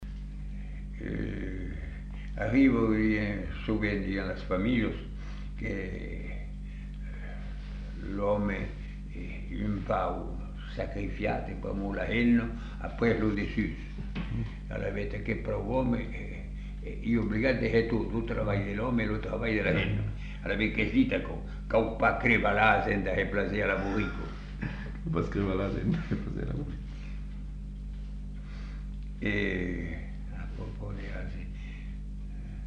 Aire culturelle : Savès
Lieu : Masseube
Effectif : 1
Type de voix : voix d'homme
Production du son : récité
Classification : proverbe-dicton